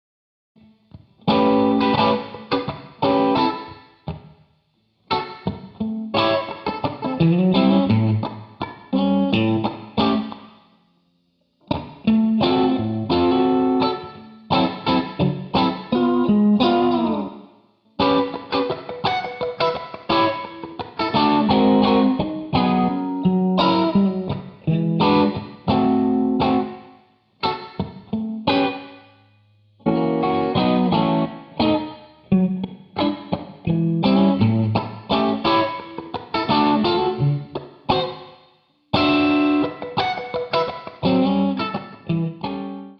RhythmusGitarre 2    und hier der Anfang des eingespielten Rhythmus-Parts 2
Rhythm.Git.2.wav